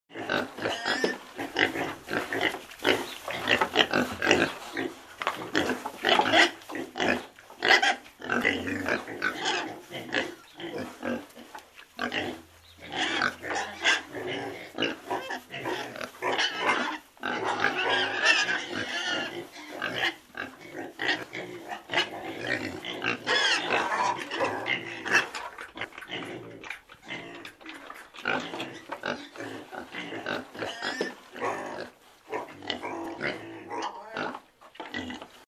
На этой странице собраны разнообразные звуки, которые издают свиньи: от забавного хрюканья до громкого визга.
стадо свиней громко хрюкает